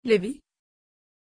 Pronunciation of Lewie
pronunciation-lewie-tr.mp3